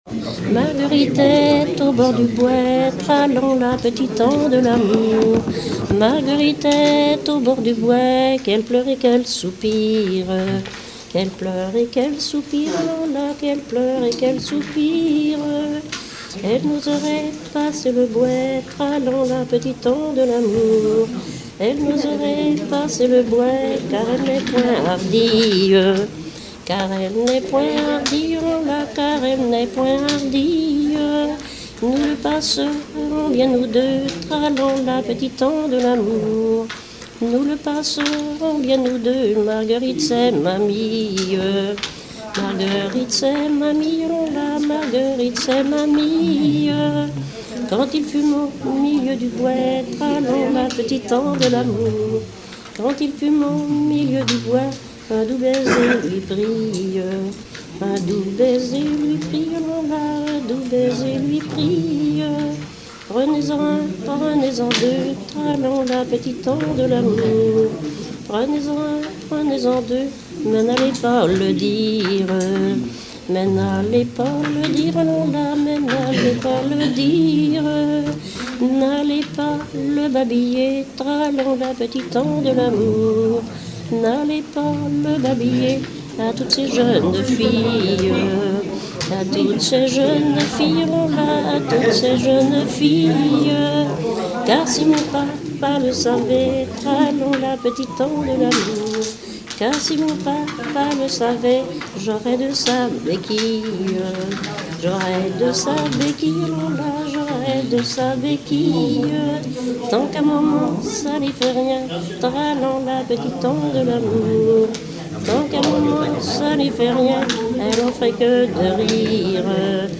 danse : ronde à trois pas
Pièce musicale inédite